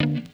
emn guitar 1.wav